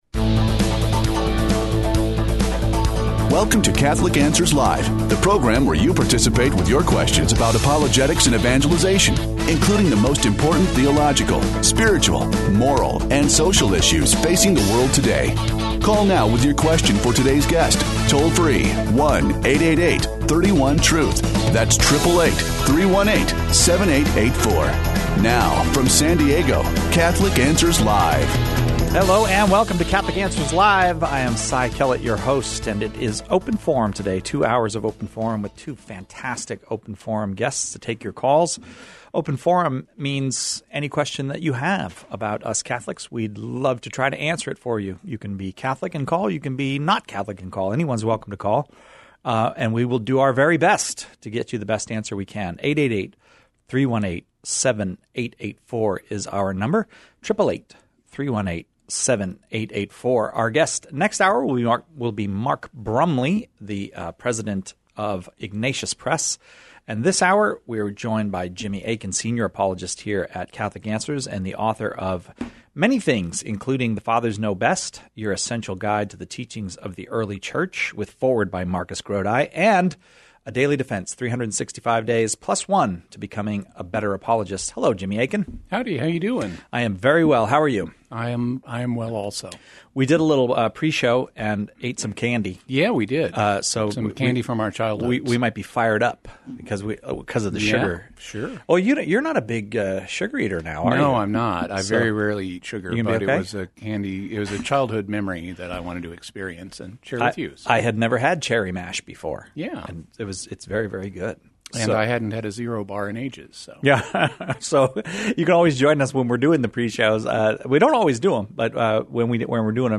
The callers choose the topics during Open Forum, with questions on every aspect of Catholic life and faith, the moral life, and even philosophical topics.